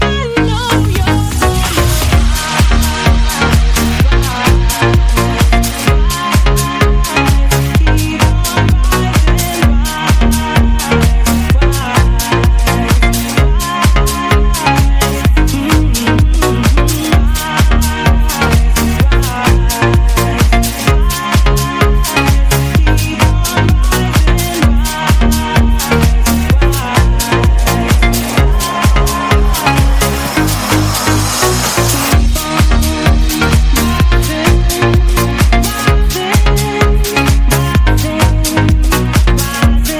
• Качество: 128, Stereo
ремиксы